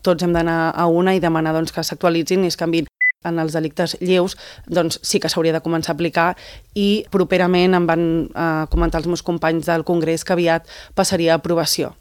Ho ha explicat a l’espai ENTREVISTA POLÍTICA de Ràdio Calella TV, on s’ha alineat amb el posicionament de Buch respecte a la necessitat de lleis més dures per combatre la multireincidència.